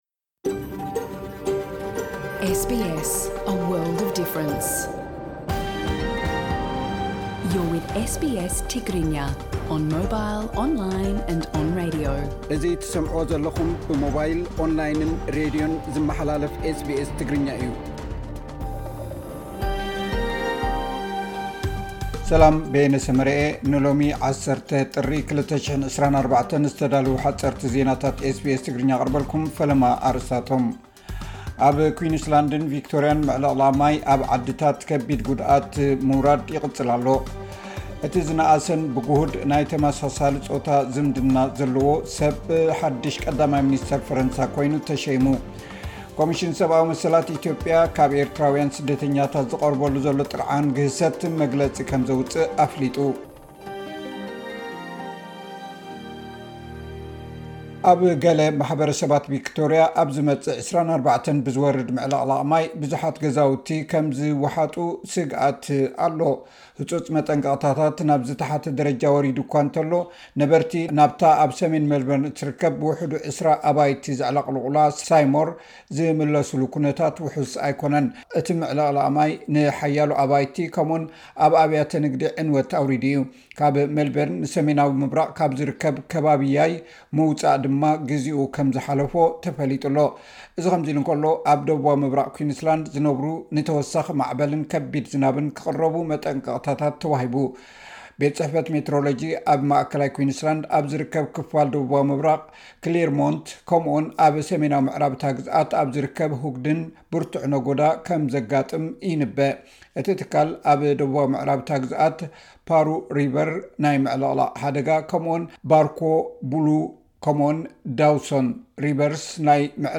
ምዕልቕላቕ ማይ ኣብ ኣውስትራሊያ፡ ሓጸርቲ ዜናታት ኤስ ቢ ኤስ ትግርኛ (10 ጥሪ 2024)